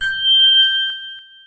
Case Location Bell.wav